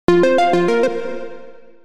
効果音のフリー素材です。
効果音1